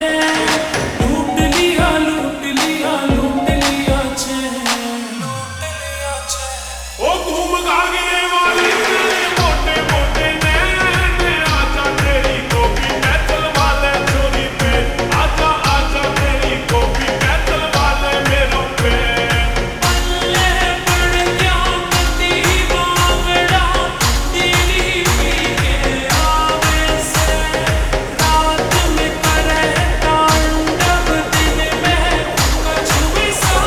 (Slowed + Reverb)